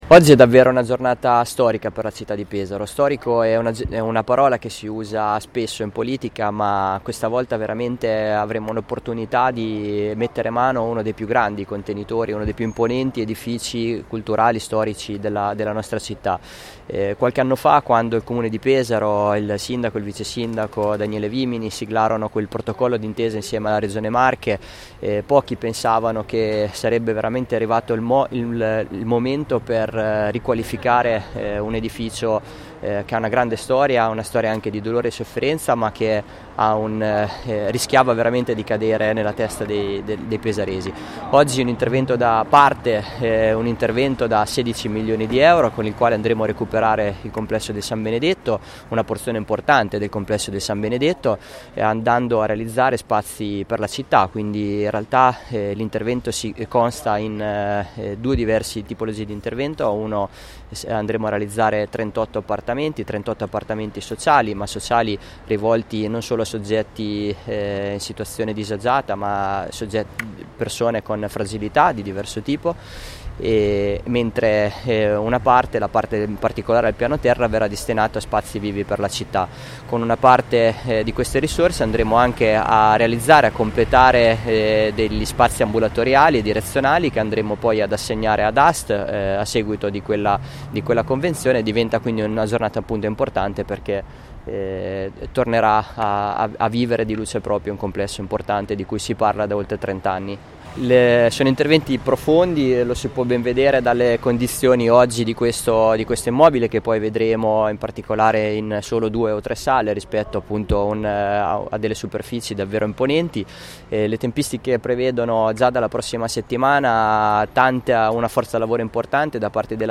L’intervento interesserà la parte del complesso di proprietà del Comune che si affaccia su corso XI Settembre e che si allunga da un lato, su via Belvedere e dall’altro su via Mammolabella. Per un totale di 5.775 mq di superfici che saranno oggetto di uno dei cantieri più imponenti, complessi e delicati, che il Comune abbia mai seguito. ai nostri microfoni: Riccardo Pozzi, Assessore alle Nuove Opere, Andrea Biancani, Sindaco di Pesaro e Daniele Vimini, Vicesindaco Assessore alla Ricerca di finanziamenti nazionali e comunitari.